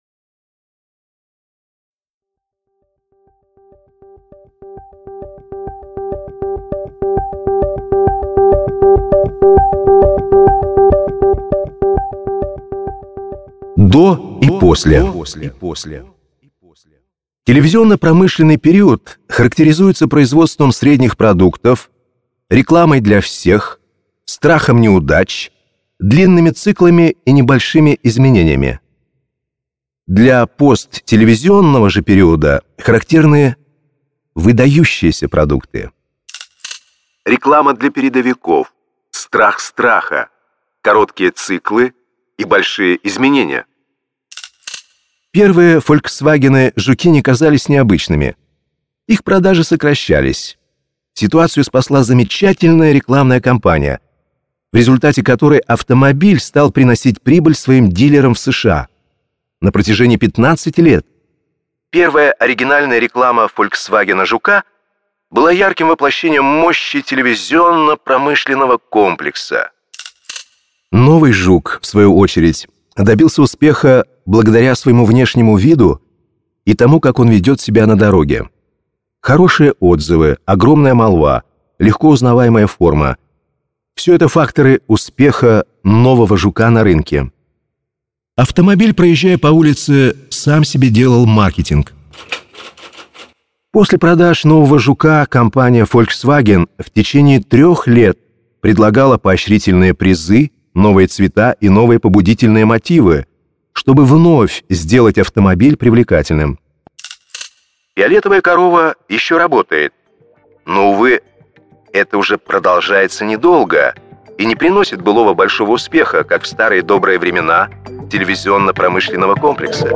Аудиокнига Фиолетовая корова.